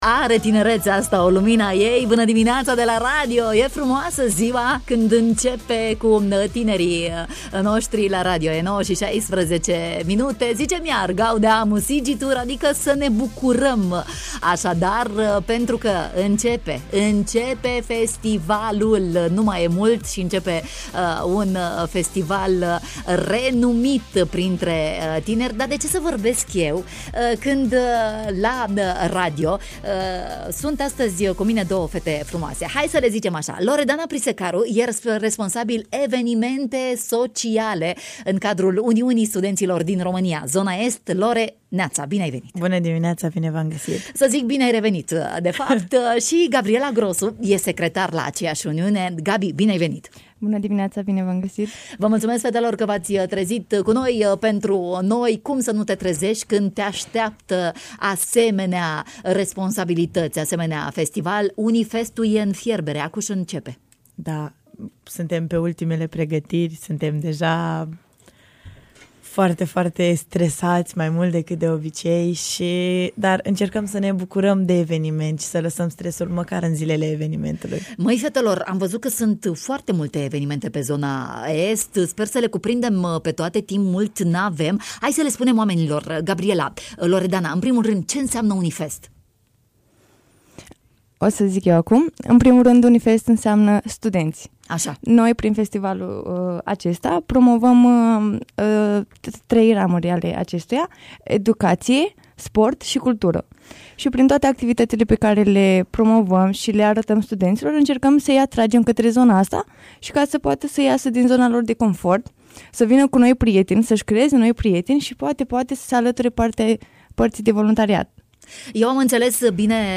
în direct la matinalul de la Radio România Iași